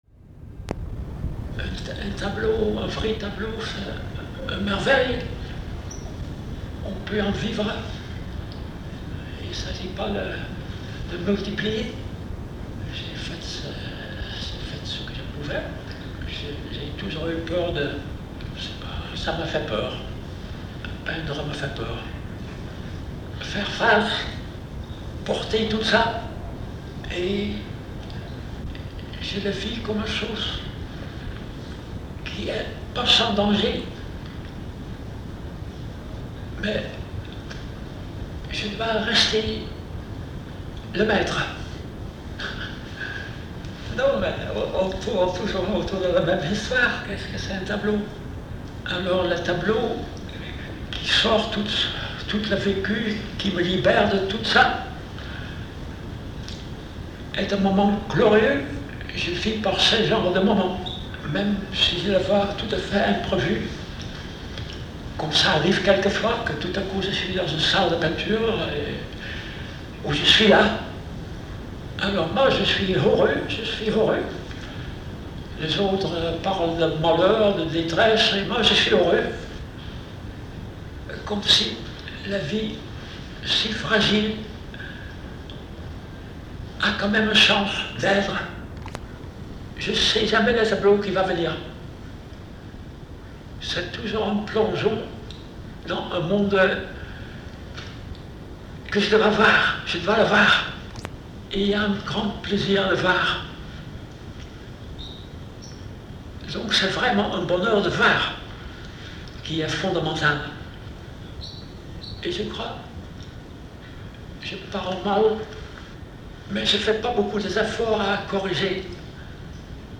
Excerpt from an interview of Bram Van Velde by Charles Juliet